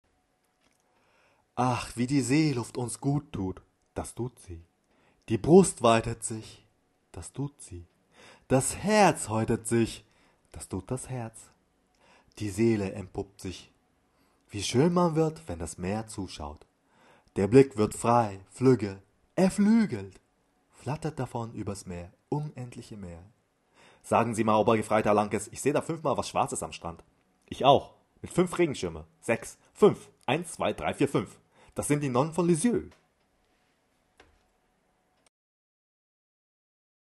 Sprecher japanisch, Profisprecher, für Werbung und Industrie
Kein Dialekt
Sprechprobe: eLearning (Muttersprache):